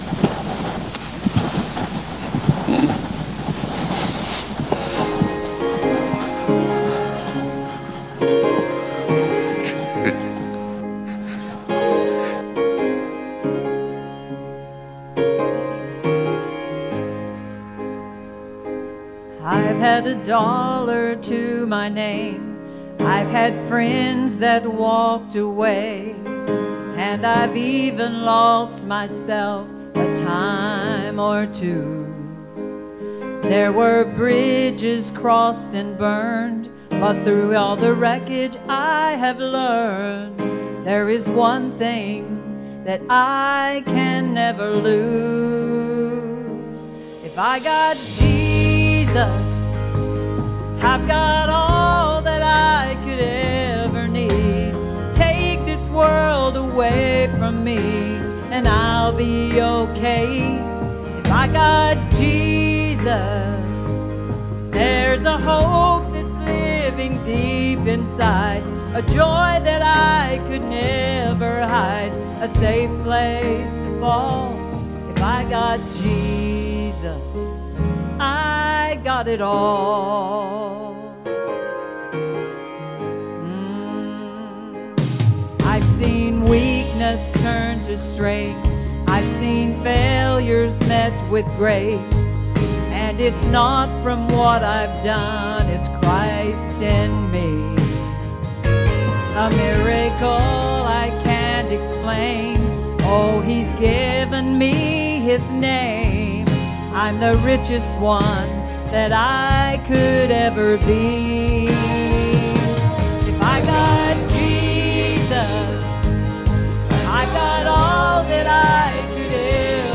Sunday Morning Worship
Enjoy The Sermon Today as We Are Sure You Will Be Blessed.